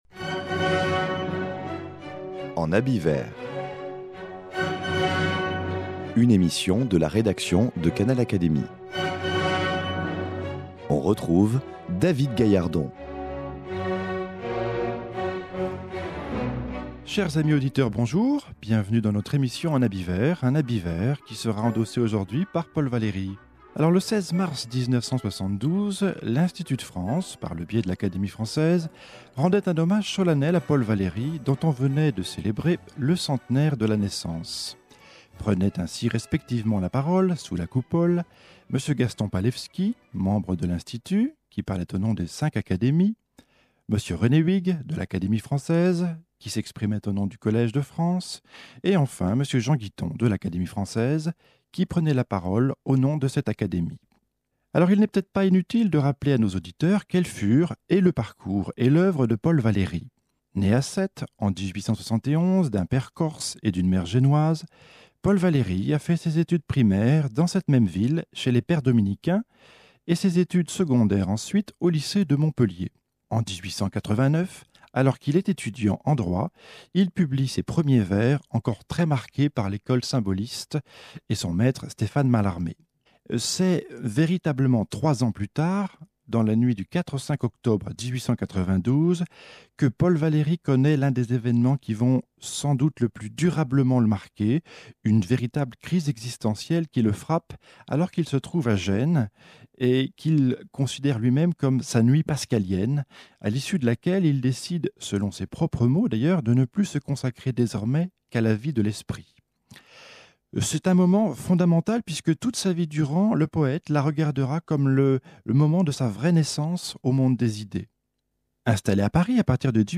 Le 16 mars 1972, lui était rendu à l’Institut de France un vibrant hommage. Prenaient respectivement la parole pour lui brosser un portrait à trois voix : Gaston Palewski, de l’Institut ; René Huyghe, de l’Académie française et Jean Guitton, de l’Académie française.